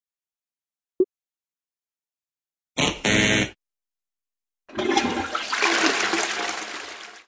[2048x2048] No symbol, "1000 Seconds" Fail Sound Effect